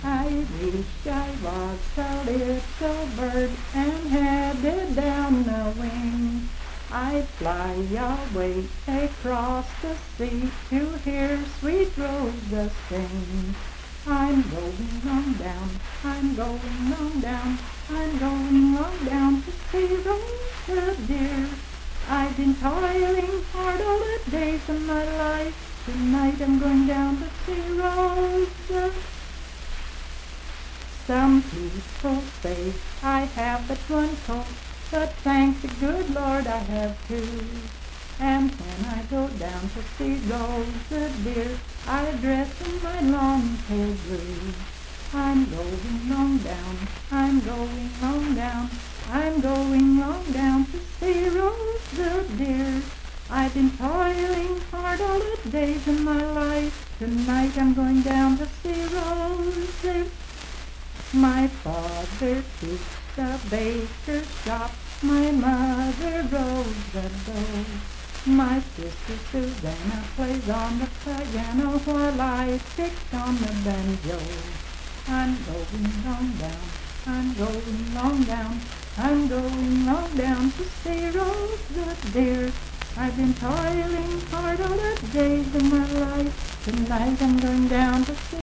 Unaccompanied vocal music
Miscellaneous--Musical
Voice (sung)